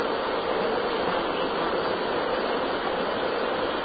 That seems to be the question I was asked when I did an EVP session at a nursing home.